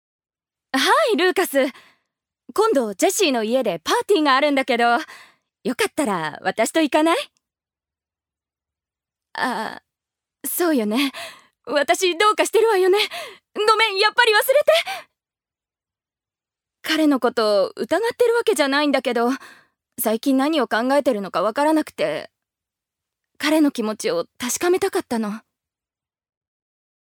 ジュニア：女性
セリフ１